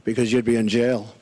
Trump saying "bc you'd be in jail"